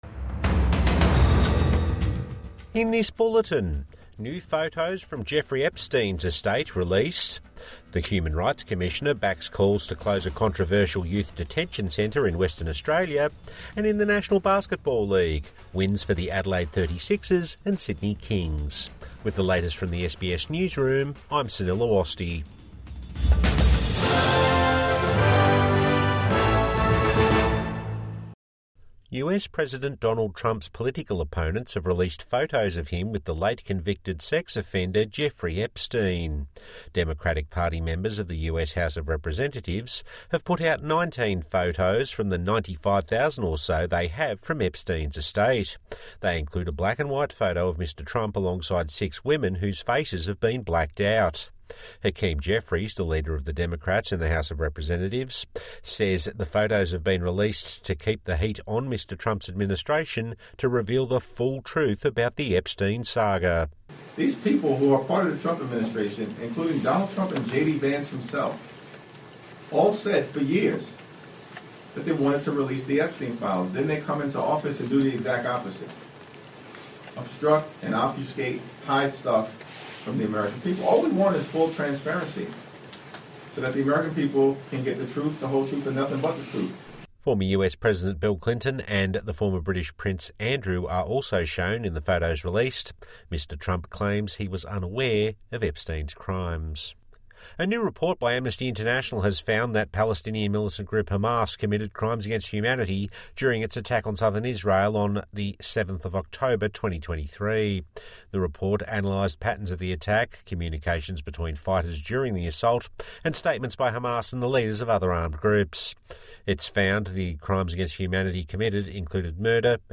US Democrats release photos of Trump with Epstein | Morning News Bulletin 13 December 2025